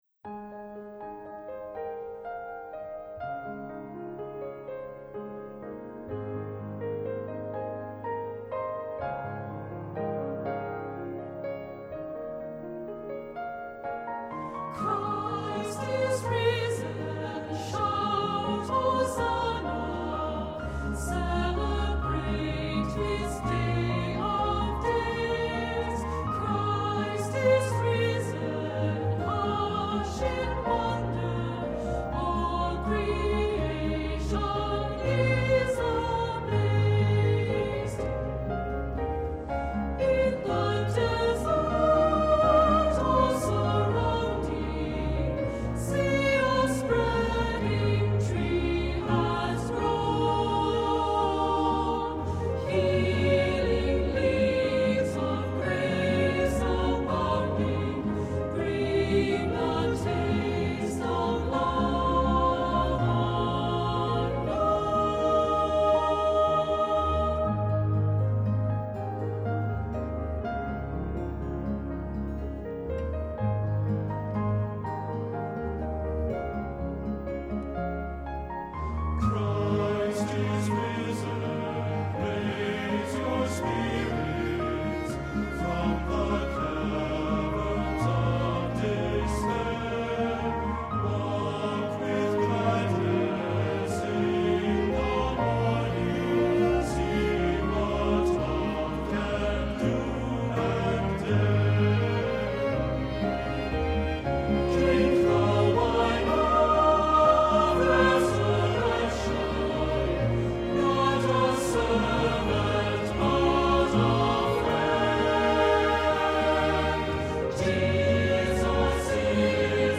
Accompaniment:      Keyboard, Trumpet in B-flat;Trumpet in C
Music Category:      Christian
Trumpet and string quartet parts are optional.